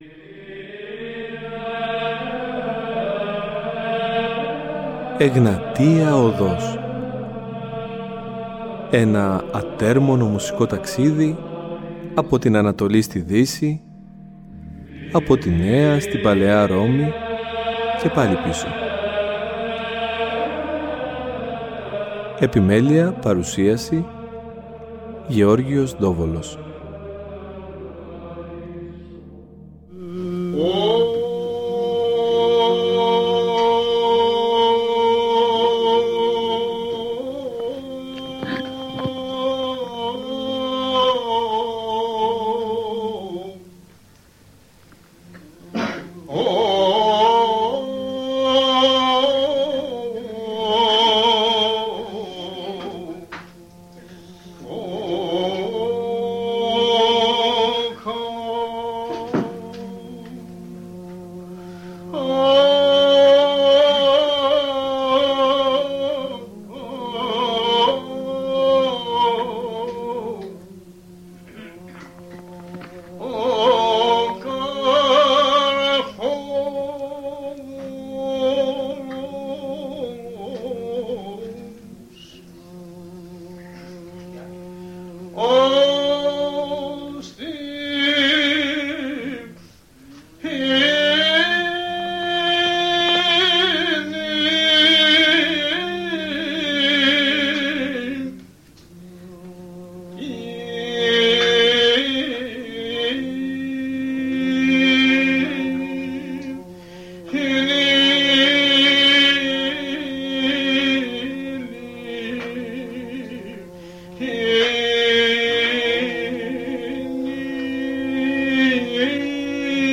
Η Εγνατία Οδός συνεχίζει το εκτενές αφιέρωμα με συνεντεύξεις και σπάνιο ηχητικό υλικό από Χιώτες ψάλτες και μουσικοδιδασκάλους, οι οποίοι άφησαν ανεξίτηλη σφραγίδα στη ιερή τέχνη του Δαμασκηνού.